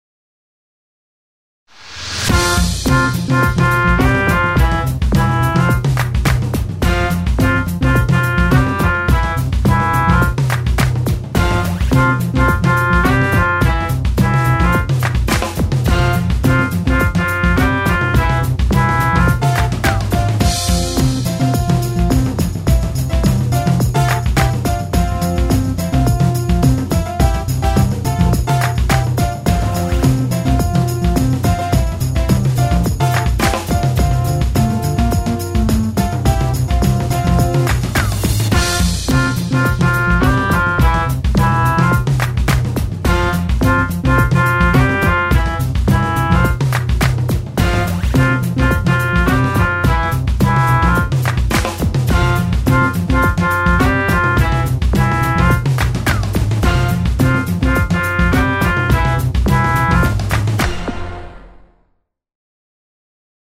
BGM
ショート明るい激しい